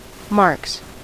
Ääntäminen
Ääntäminen US : IPA : [mɑɹks] Tuntematon aksentti: IPA : /ˈmɑːks/ Haettu sana löytyi näillä lähdekielillä: englanti Käännöksiä ei löytynyt valitulle kohdekielelle.